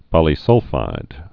(pŏlē-sŭlfīd)